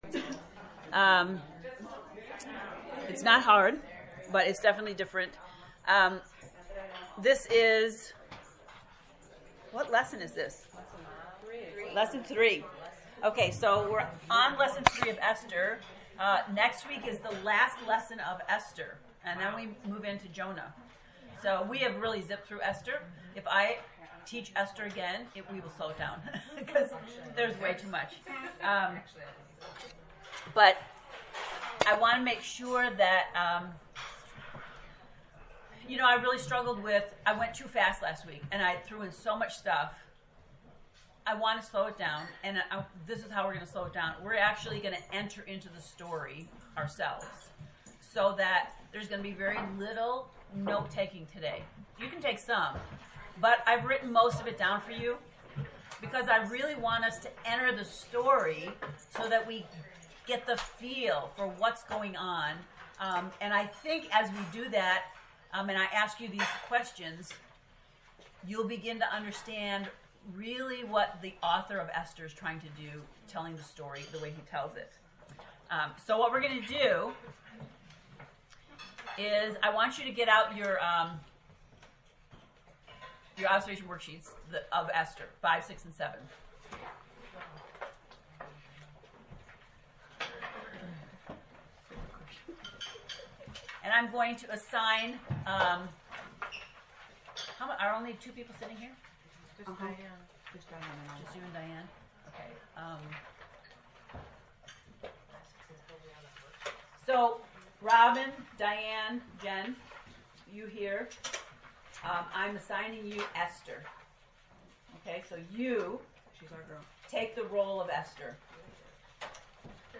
ESTHER lesson 3
esther-lect-3.mp3